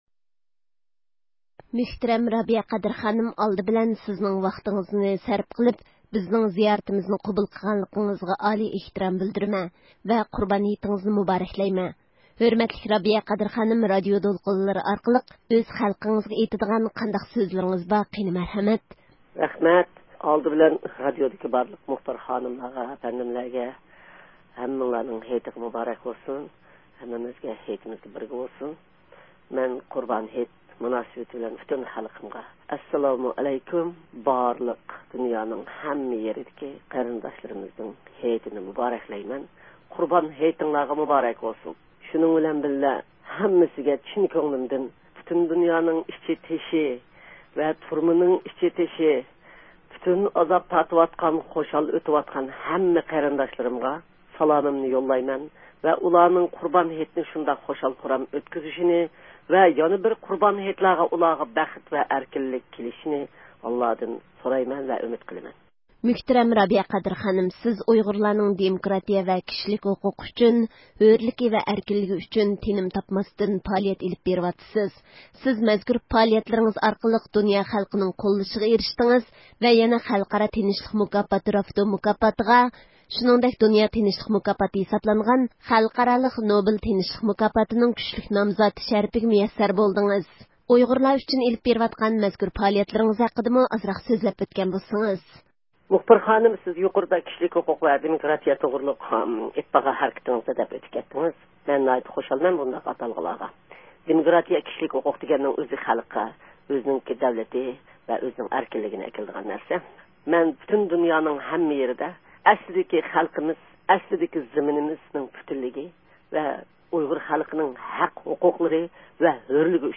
قۇربان ھېيت مۇناسىۋىتى بىلەن ئۇيغۇر مىللى ھەرىكىتىنىڭ يېتەكچىسى رابىيە قادىر خانىم بىلەن سۆھبەت – ئۇيغۇر مىللى ھەركىتى